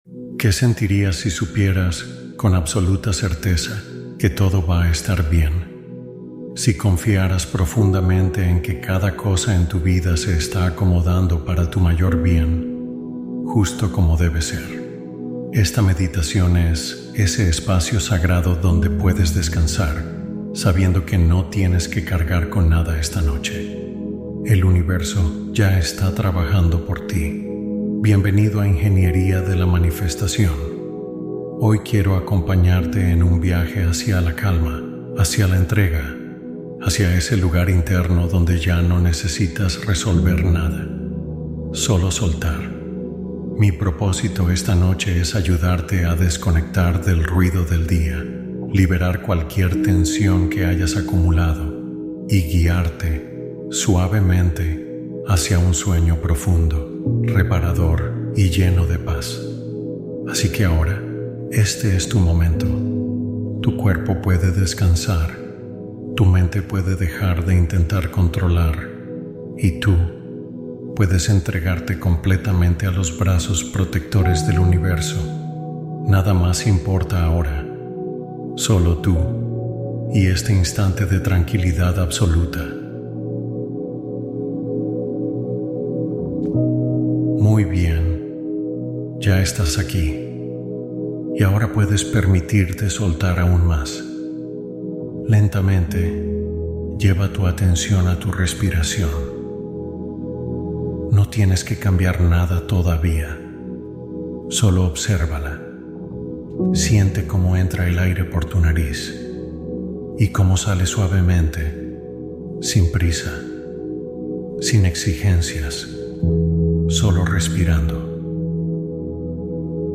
Un espacio nocturno de hipnosis para cultivar confianza y equilibrio interno